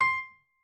piano6_27.ogg